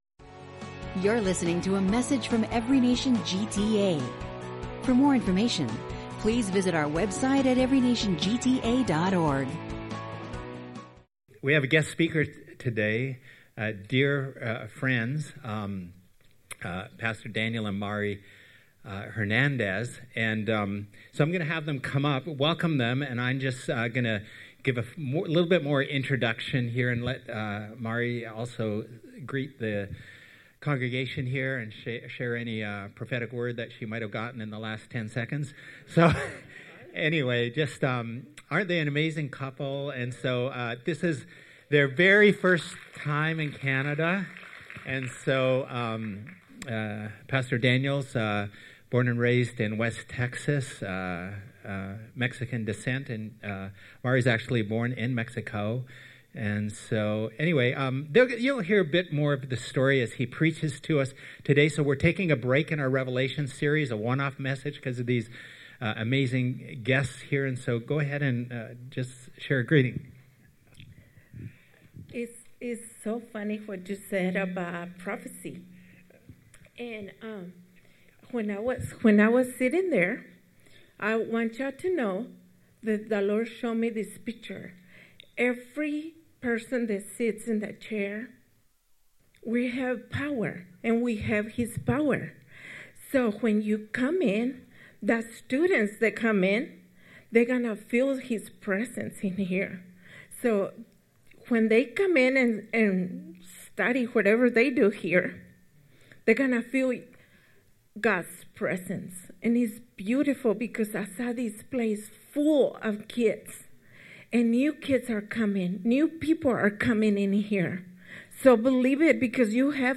Series One-off messages